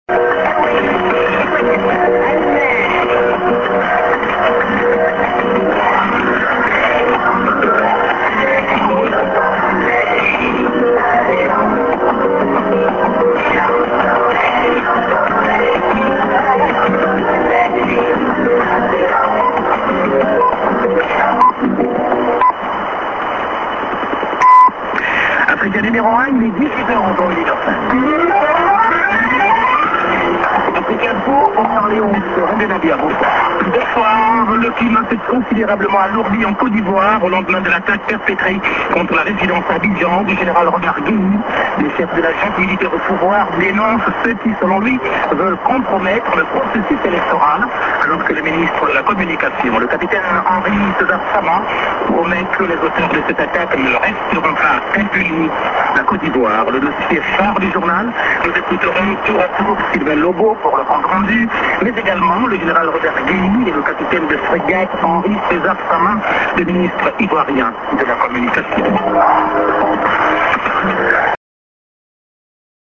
Mid. Music->TS->ID(men)->SJ->ANN->music